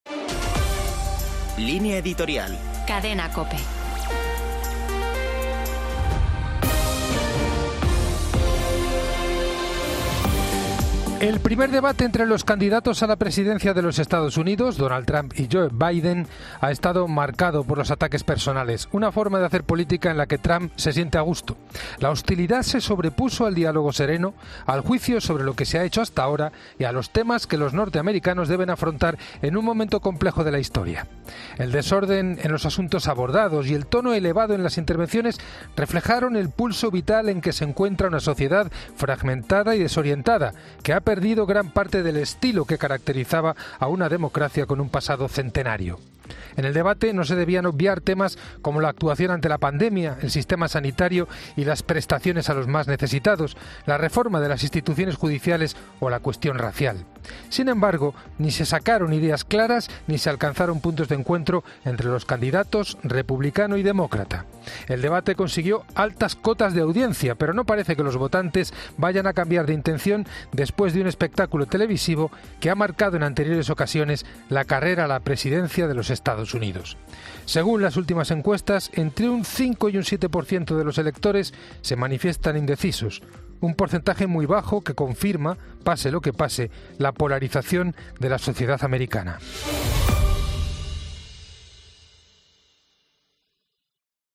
Laudes